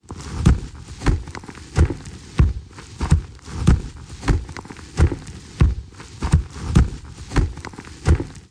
棺材怪脚步_连续.ogg